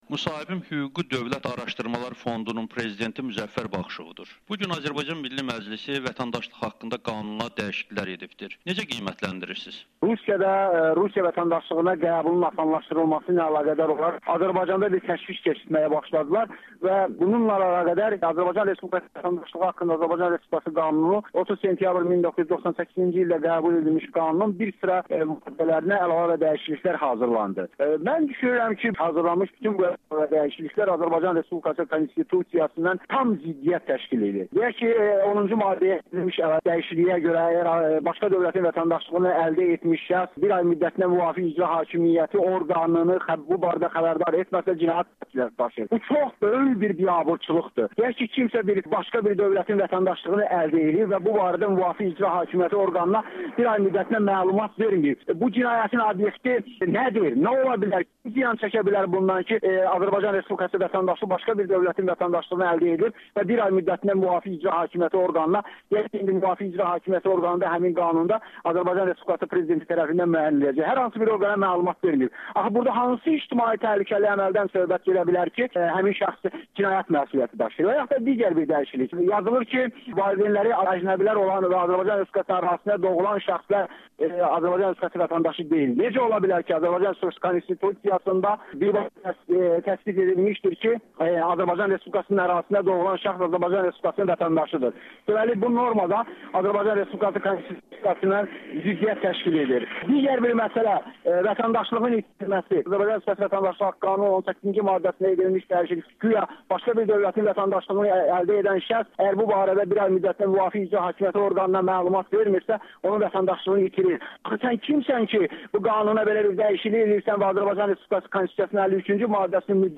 Vətəndaşlıq haqda qanuna dəyişikliklər konstitutsiyaya ziddir [Audio-Müsahibə]